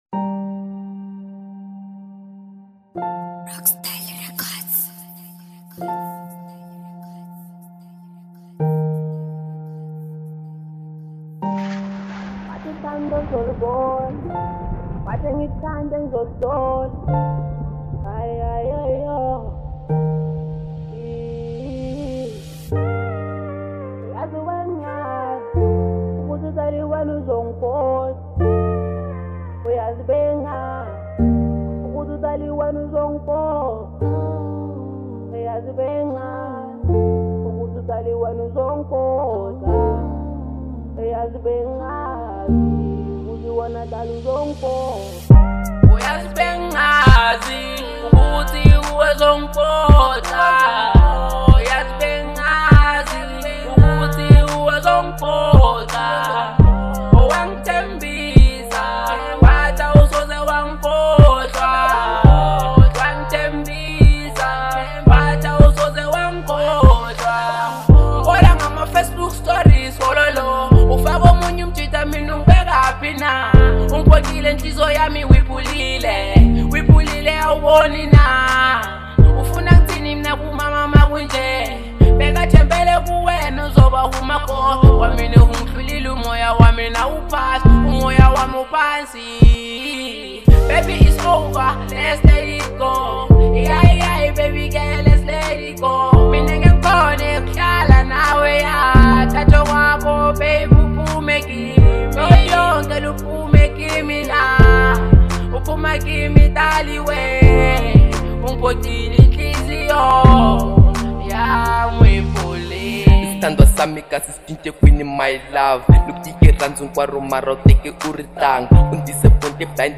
03:17 Genre : Hip Hop Size